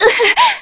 Cassandra-Vox_Damage_01_kr.wav